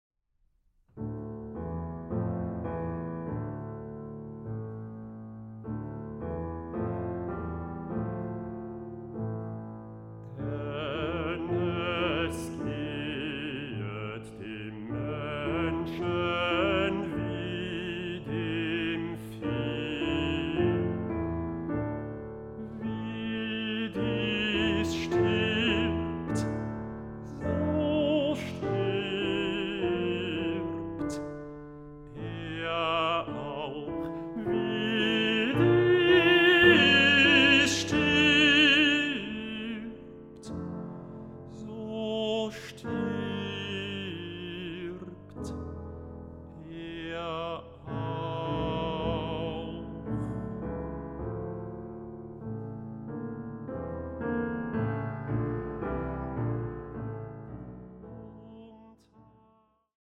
Bassbariton
Klavier
Aufnahme: Ölbergkirche Berlin, 2024